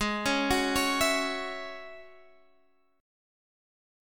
G#7sus4#5 chord